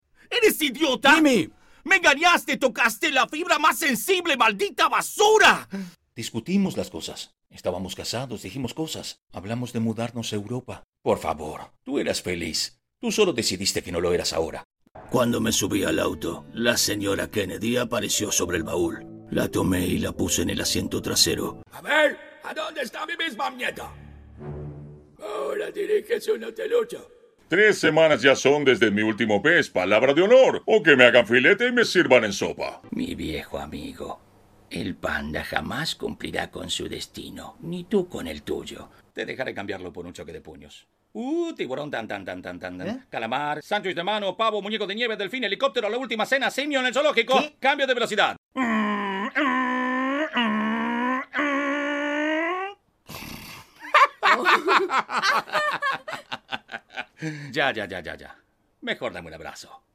Poseo estudio propio con calidad profesional, y me autobgrabo para varios estudios.
Sprechprobe: Sonstiges (Muttersprache):